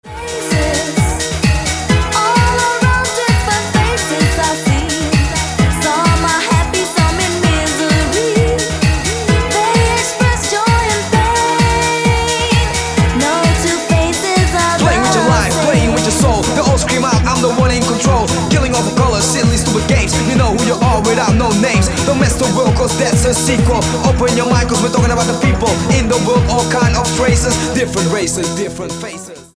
• Качество: 256, Stereo
мужской вокал
женский вокал
dance
Electronic
club
Rap
Eurodance
techno
vocal